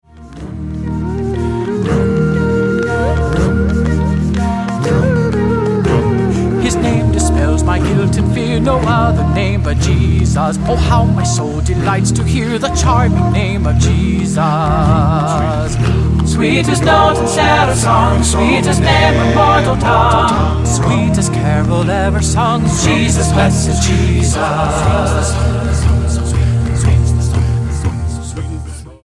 STYLE: Hymnody